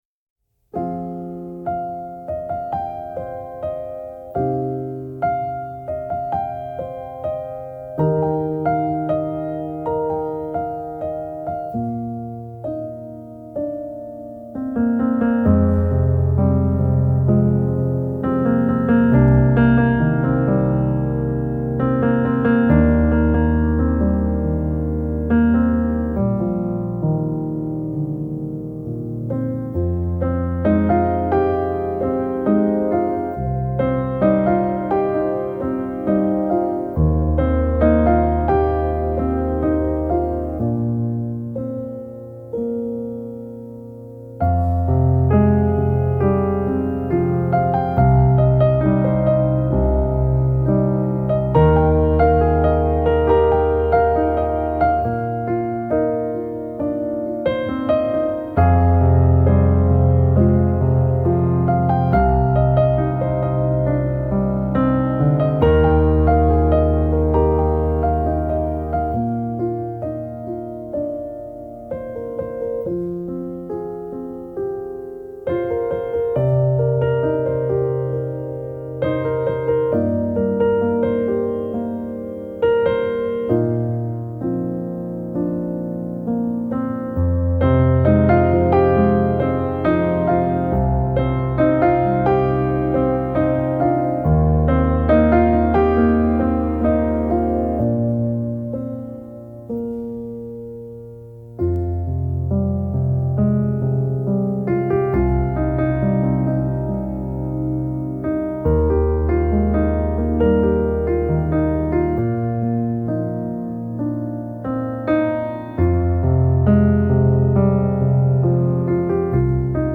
موسیقی کنار تو
آرامش بخش , پیانو , عاشقانه , موسیقی بی کلام